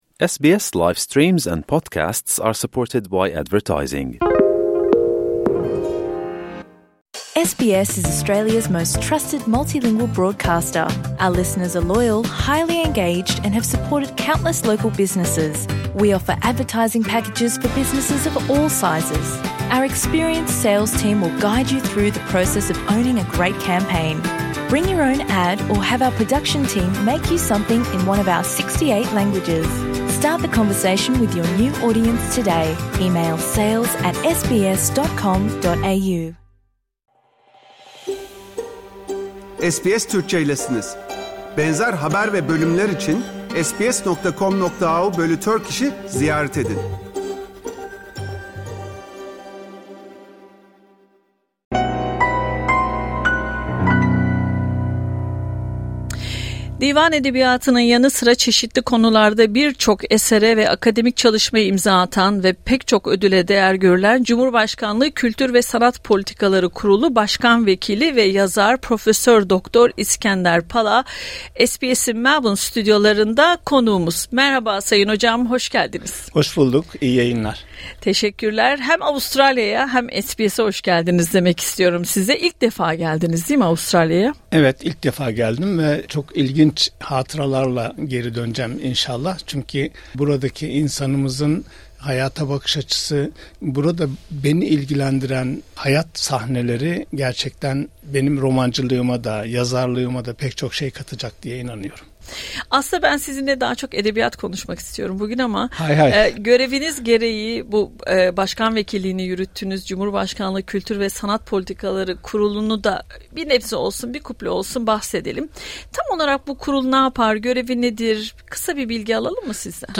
Divan edebiyatının yanı sıra çeşitli konularda birçok esere ve akademik çalışmaya imza atan ve pek çok ödüle değer görülen Cumhurbaşkanlığı Kültür ve Sanat Politikaları Kurulu Başkan Vekili ve yazar Prof. Dr. İskender Pala, SBS’in Melbourne stüdyolarında konuğumuz oldu.